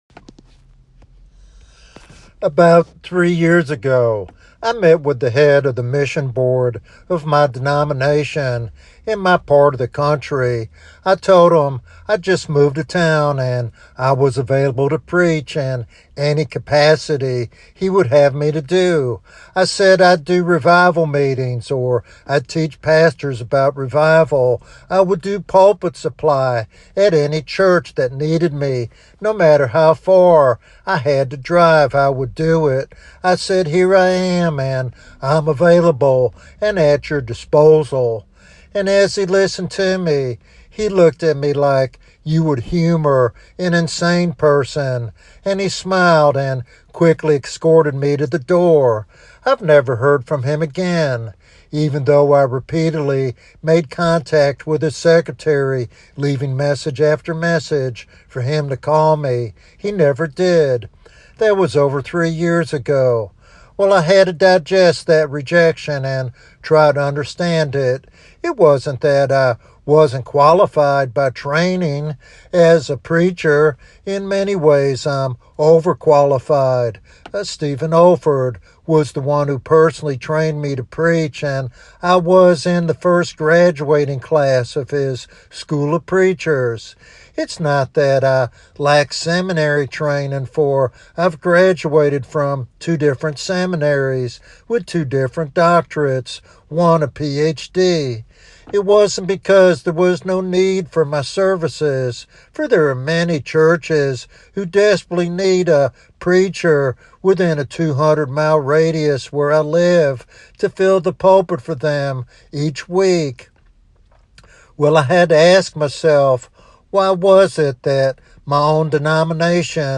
In this compelling sermon